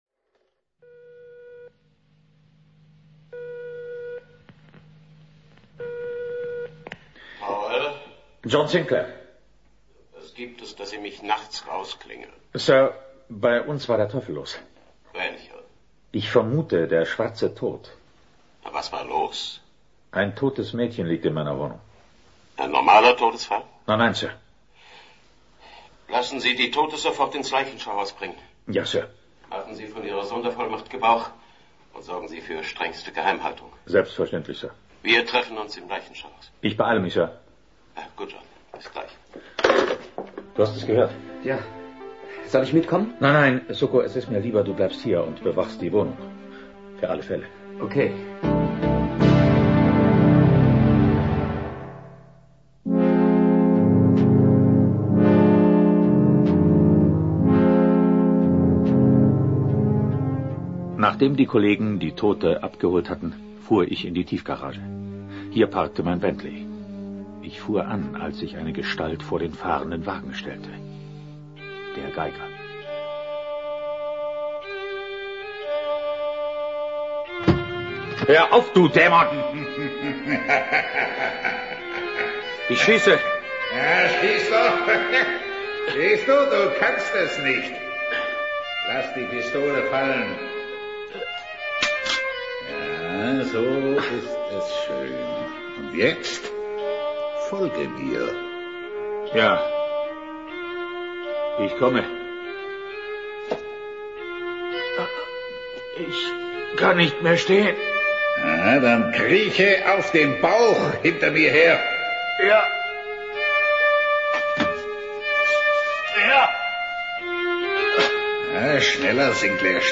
Sobald die Teufelsgeige erklingt, verändert sich die Stimmung.
Sein Klang wirkt verführerisch und zugleich tödlich.
Musik, Geräusche und Dialoge greifen dabei gut ineinander. Der Grusel entsteht weniger durch laute Effekte als durch die dichte Atmosphäre.